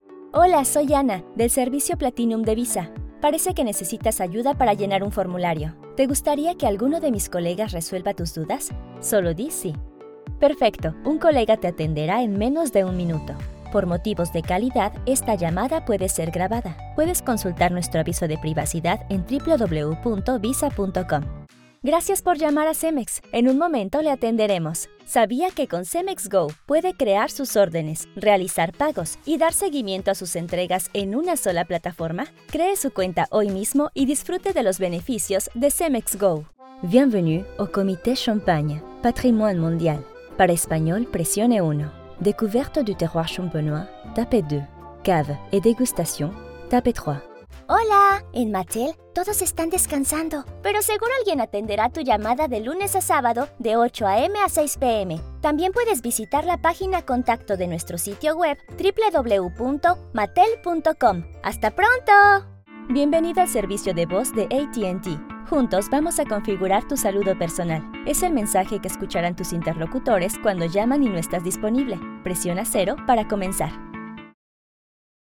Spanish (Latin-America)
Young, Natural, Friendly, Soft, Corporate
Telephony